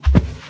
sounds / mob / cow / step4.ogg
step4.ogg